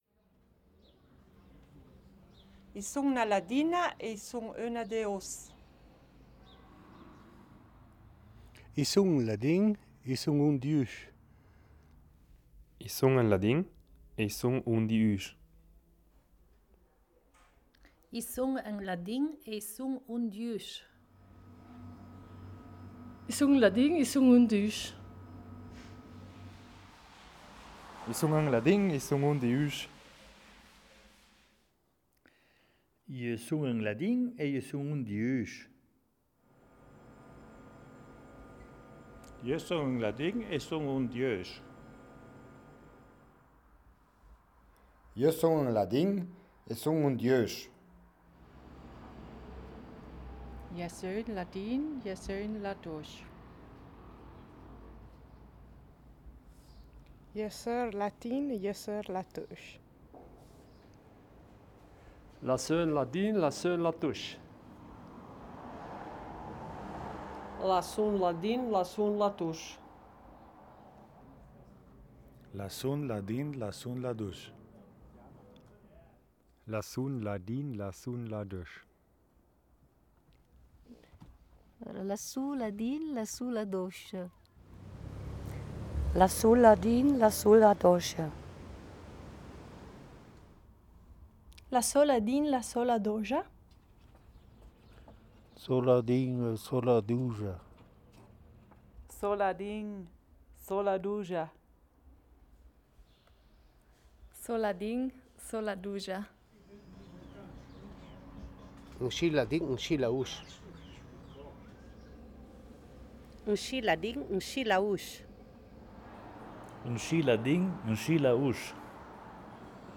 Each person joining the game brings in a totally new pitch and tonality typical of their language and dialect environment, thus gradually changing the sentence as the game goes on. An arbitrary group of passers-by thus forms a new language pool that you can listen to at the exhibition and check the phonetic transcription.
telefon di mác (Audio), 2016, 28 Sprachaufnahmen, 02:18 min